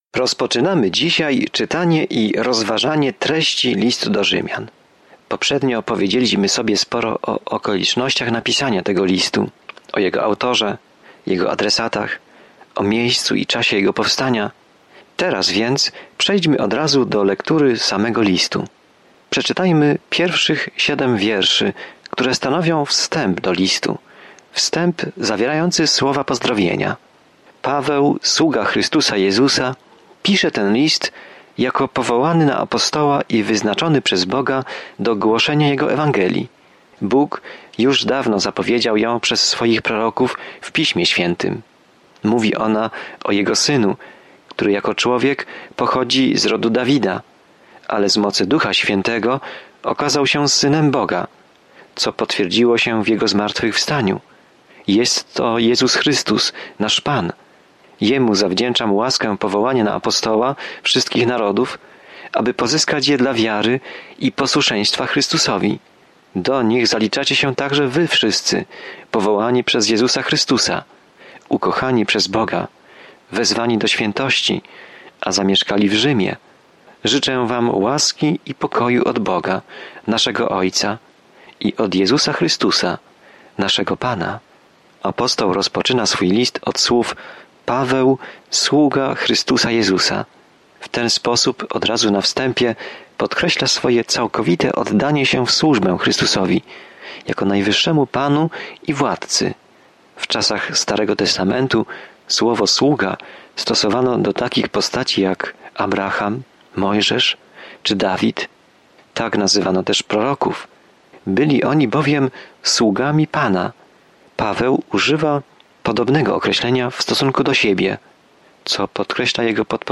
Pismo Święte Rzymian 1:1-7 Dzień 1 Rozpocznij ten plan Dzień 3 O tym planie List do Rzymian odpowiada na pytanie: „Jaka jest dobra nowina?” I jak każdy może uwierzyć, zostać zbawiony, uwolniony od śmierci i wzrastać w wierze. Codzienna podróż przez List do Rzymian, słuchanie studium audio i czytanie wybranych wersetów słowa Bożego.